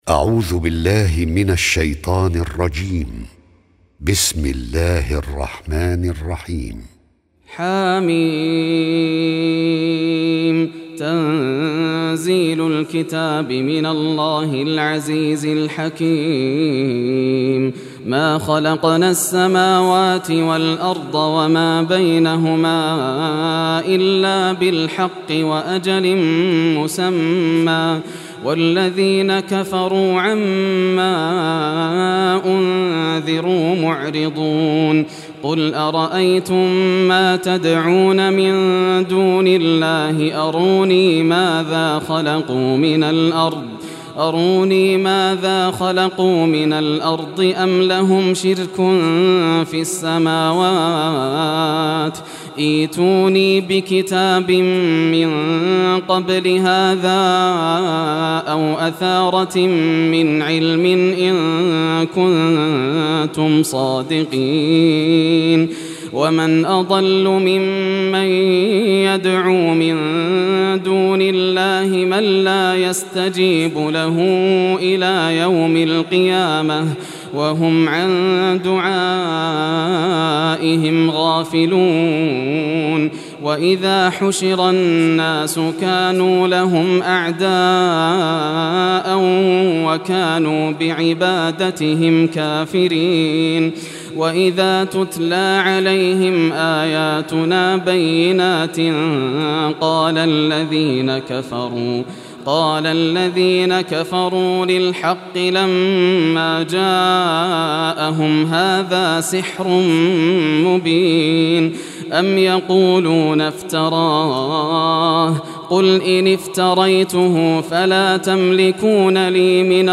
Surah Al-Ahqaf Recitation by Yasser al Dosari
Surah Al-Ahqaf, listen or play online mp3 tilawat / recitation in Arabic in the beautiful voice of Sheikh Yasser al Dosari.